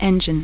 c_engine.mp3